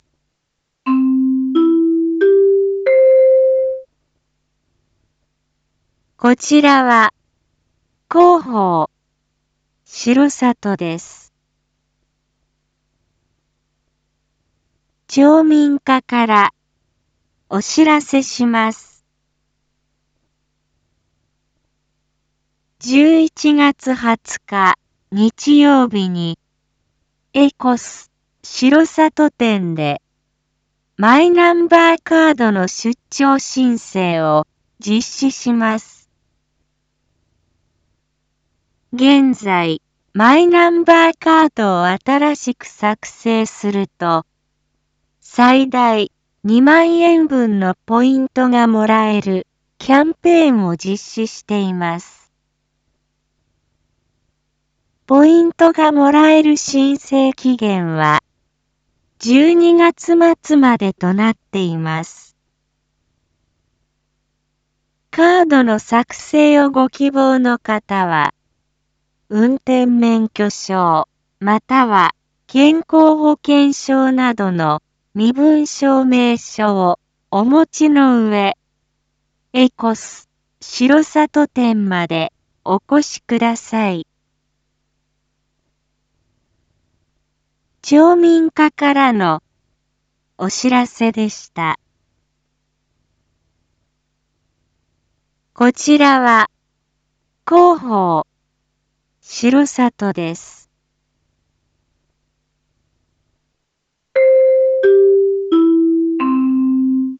一般放送情報
Back Home 一般放送情報 音声放送 再生 一般放送情報 登録日時：2022-11-19 19:01:48 タイトル：R4.11.19 19時放送分 インフォメーション：こちらは、広報しろさとです。